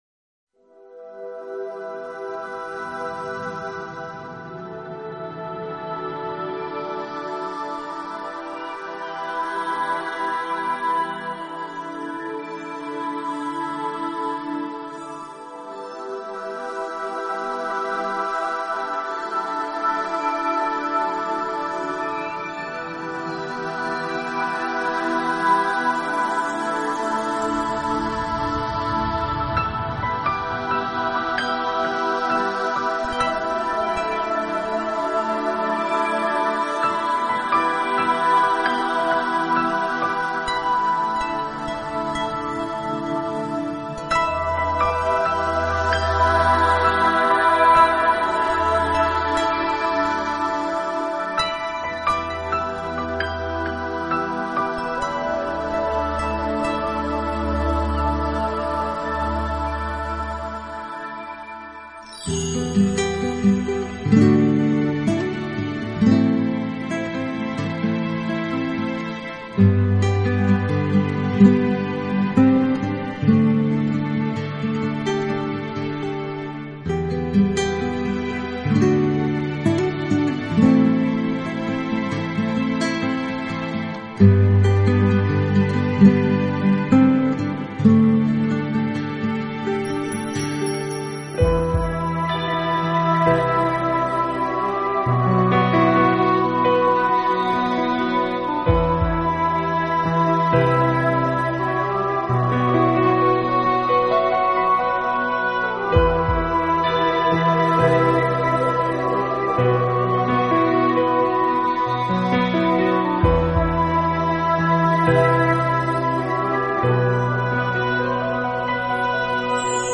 【纯音乐专辑】
Genre : New Age